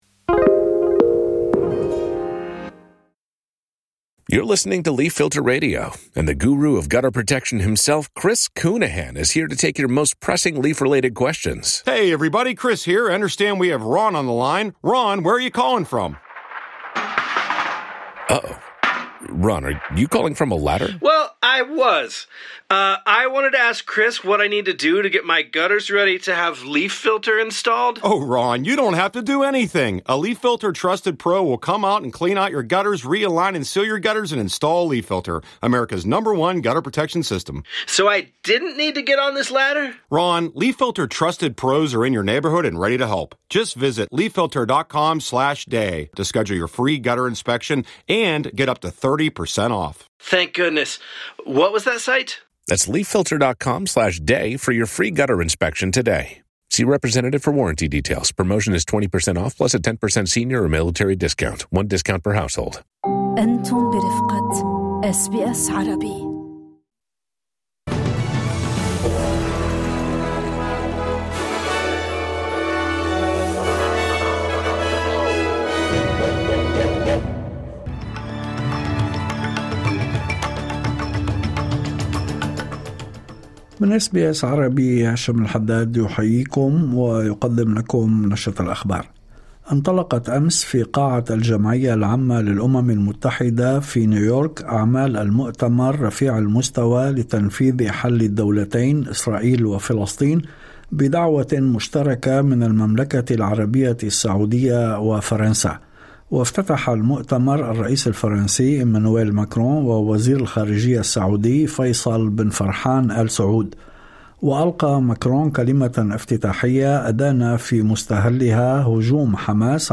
نشرة الأخبار أخبار بالعربي